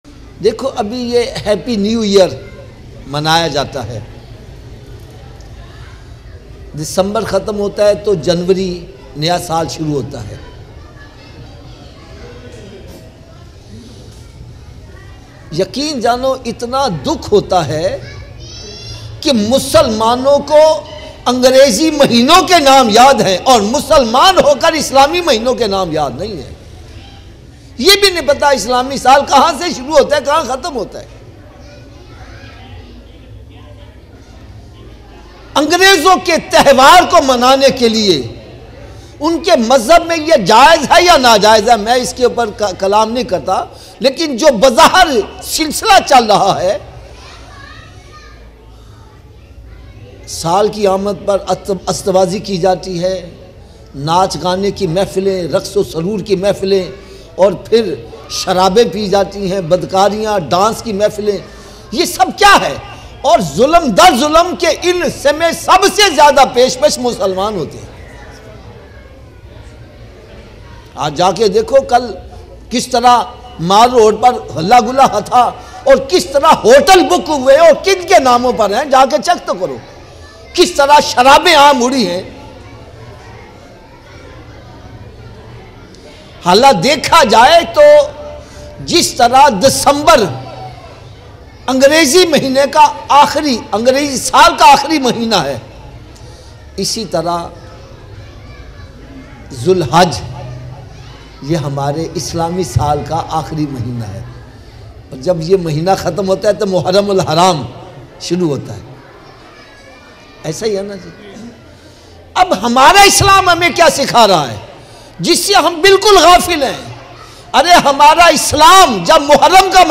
Best Islahi Bayan